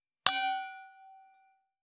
312レスタンブラー,シャンパングラス,ウィスキーグラス,ヴィンテージ,ステンレス,金物グラス,
効果音厨房/台所/レストラン/kitchen食器